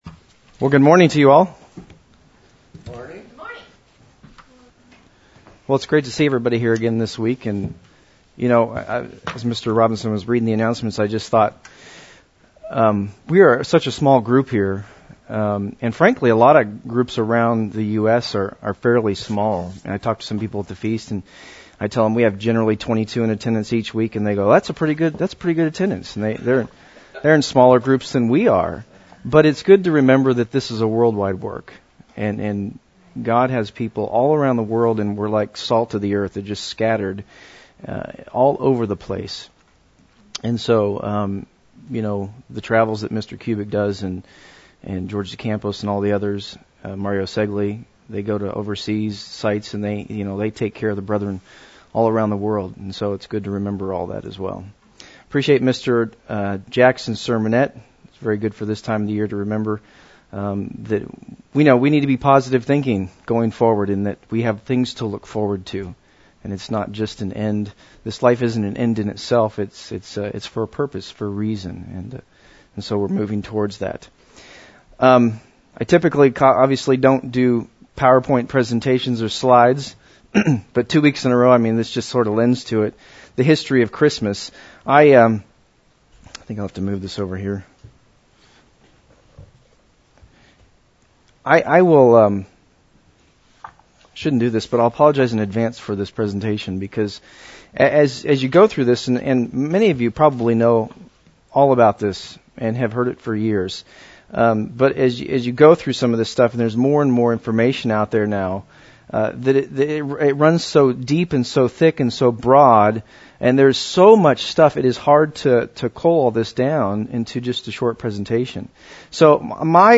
This sermon traces modern day Christmas celebrations sequentially back through history to its origins. The sheep's clothing is taken off to expose the wolf inside.